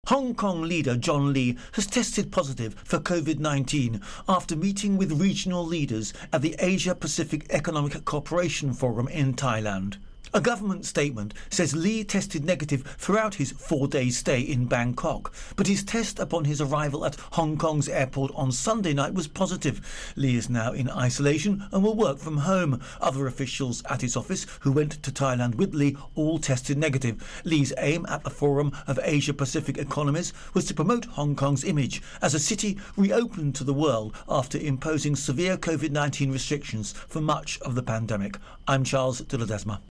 reports on Virus Outbreak Hong Kong.